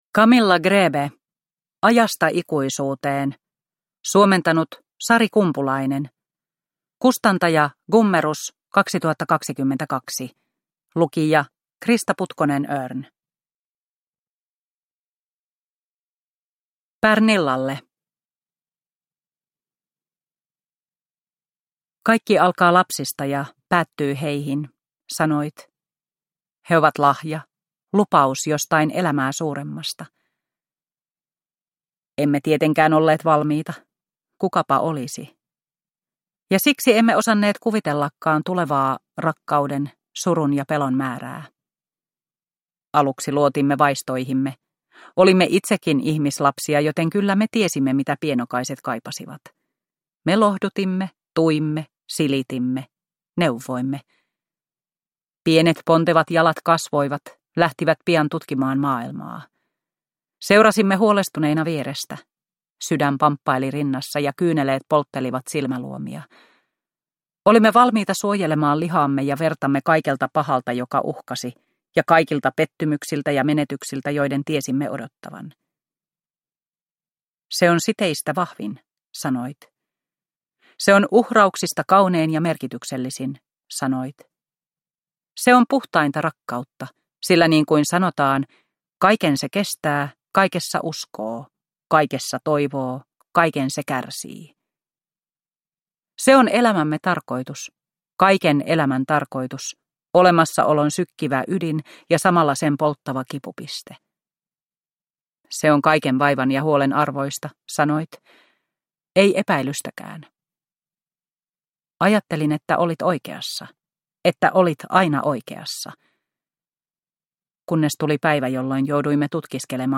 Ajasta ikuisuuteen – Ljudbok – Laddas ner